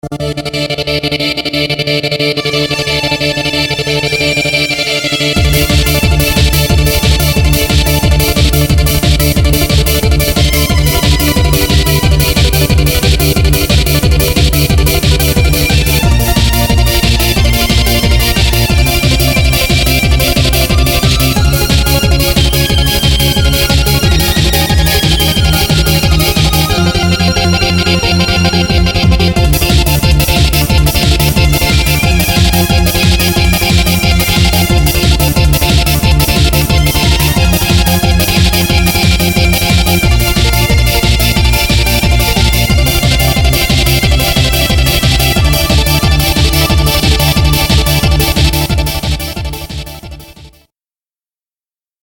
Hopefully the strings won't make your ears bleed too much (but if they do then I laugh at your pain while I destroy your space fleet! HAHA! I WILL CRUSH YOU!).